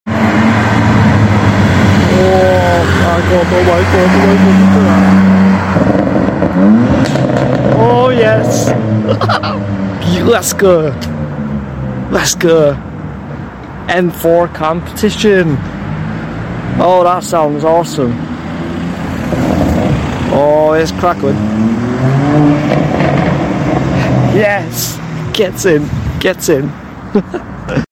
This M4 Sounded Nasty I Sound Effects Free Download